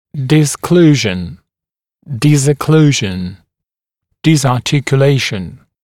[dɪs’kluːʒn] [dɪzə’kluːʒn] [dɪzɑːˌtɪkjə’leɪʃn] [дис’клу:жн] [дизэ’клу:жн] [диза:ˌтикйэ’лэйшн] дизокклюзия